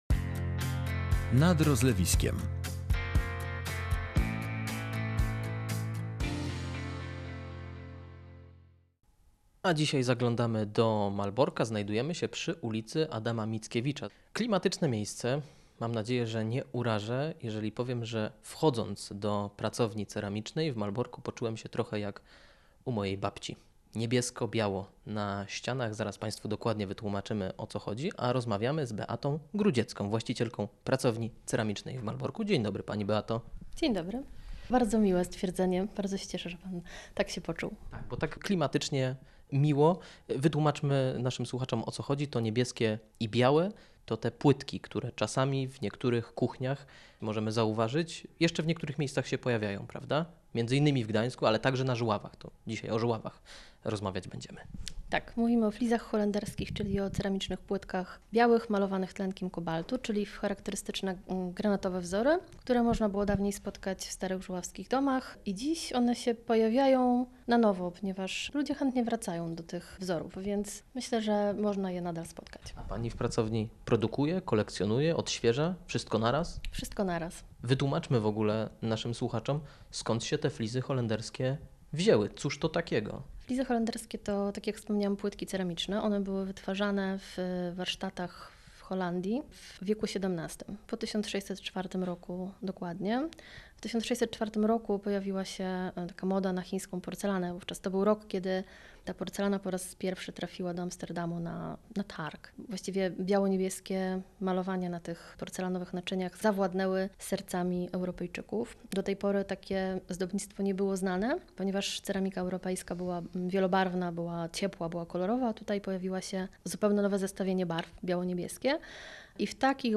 Kafle, inspirowane chińską porcelaną, były europejską odpowiedzią na dalekowschodnie arcydzieła. W pracowni ceramicznej zgłębiliśmy historię i proces tworzenia tych unikatowych ozdób, które dzisiaj wracają do łask.